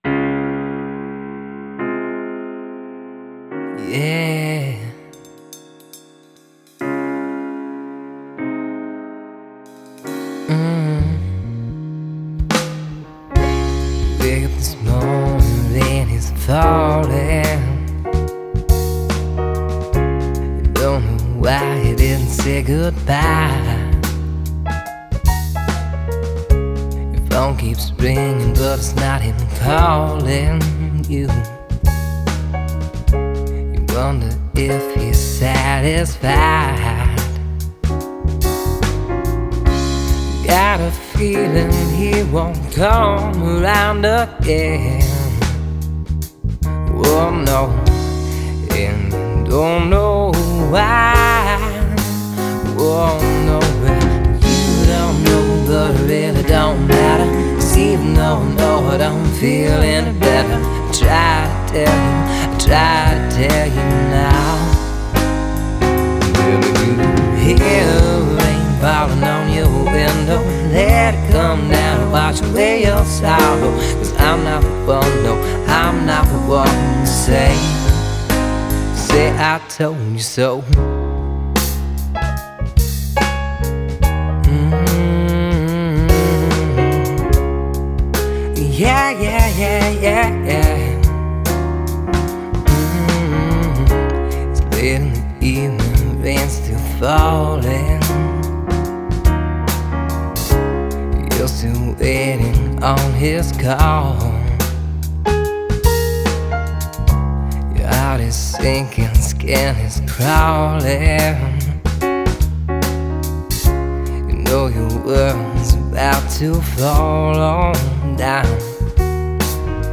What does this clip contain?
Genre: indiepop.